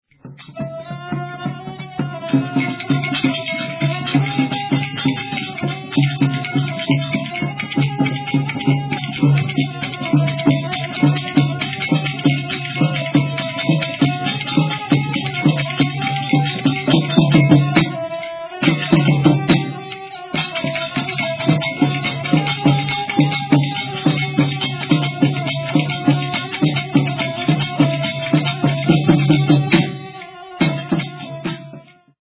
Folk Tune (1:54)